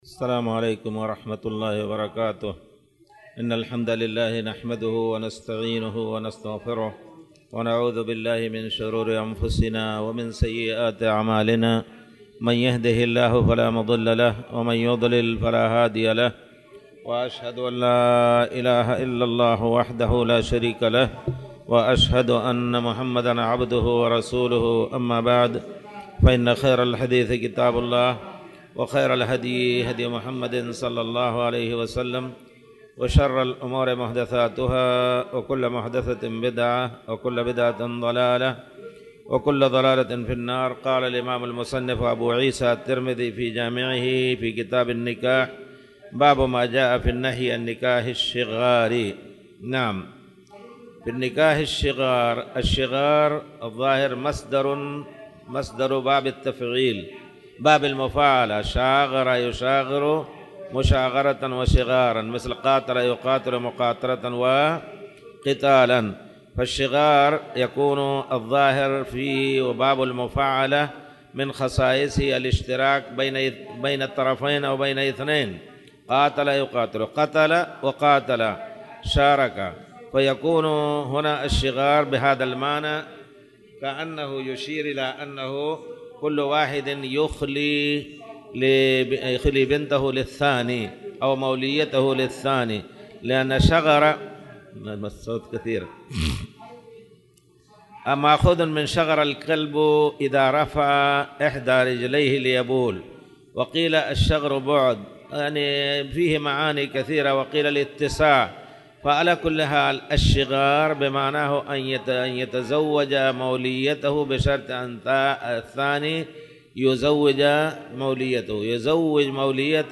تاريخ النشر ٥ محرم ١٤٣٨ هـ المكان: المسجد الحرام الشيخ